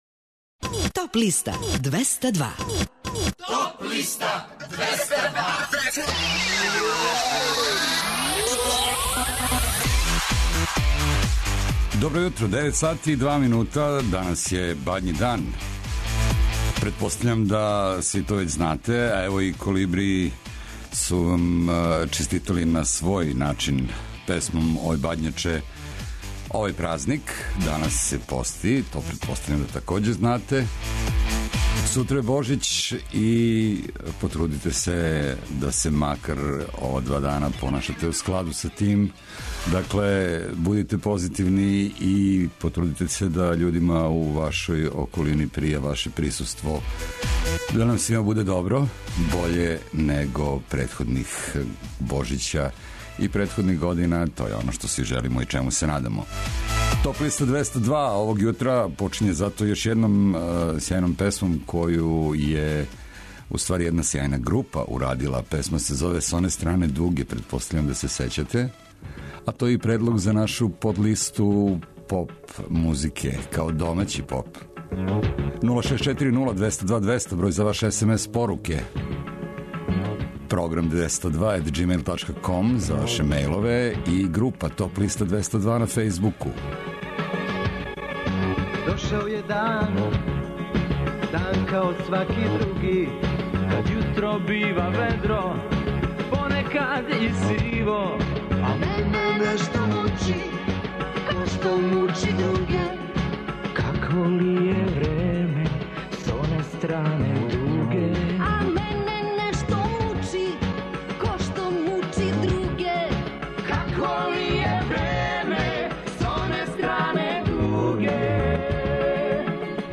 У овонедељном издању Топ листе у 2016. години слушајте новогодишње и божићне музичке нумере, иностране и домаће новитете, као и композиције које су се нашле на подлисти лектира, класика, етно, филмскe музикe...